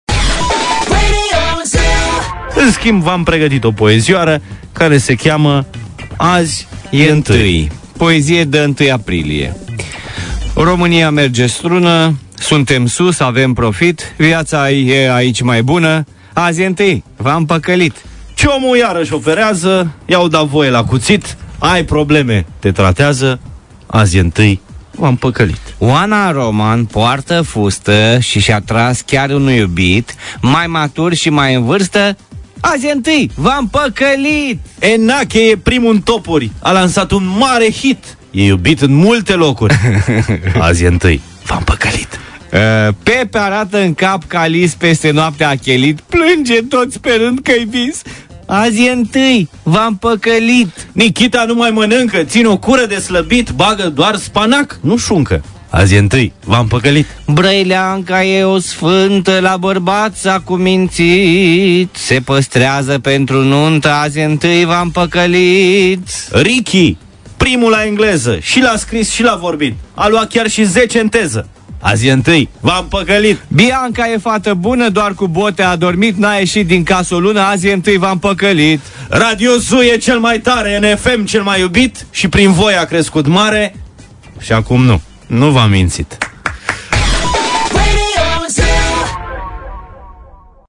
A fost suficient ca Buzdu si Morar sa zica o data poezioara de 1 aprilie in Morning ZU, ascultatorii s-au grabit sa trimita la 1815 propriile variante. Uite-asa se face un poem colectiv.
Buzdu si Morar in Morning ZU